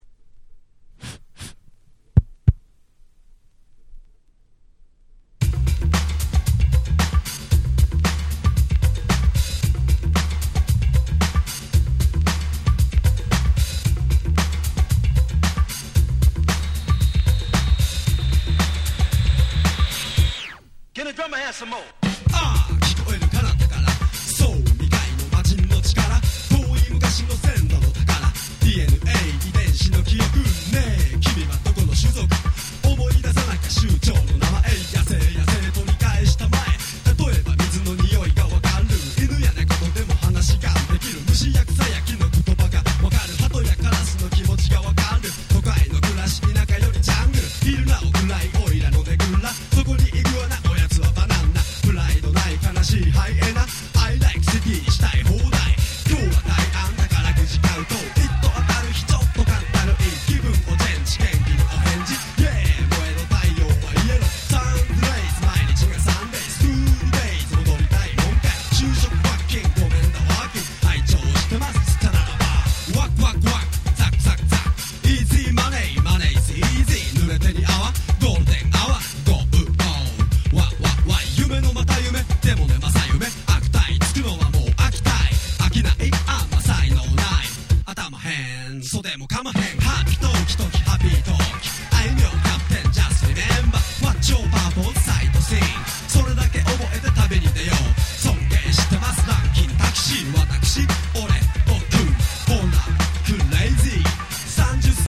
90' Japanese Hip Hop Nice Compilation !!
Japanese Old School Hip Hop」とでも言えば分かり易いですかね。